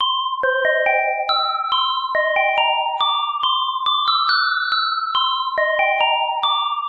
木琴 " 木琴演奏旋律C5循环
描述：C5的木琴旋律循环。
标签： 循环 旋律 气氛 音乐 Xylophon 气氛
声道立体声